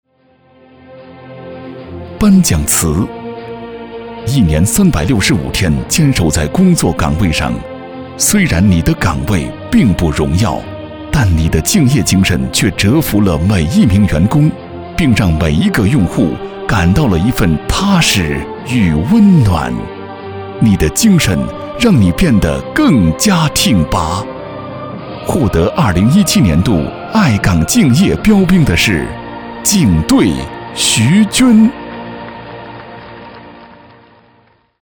配音风格： 浑厚 科技 年轻 温情 优雅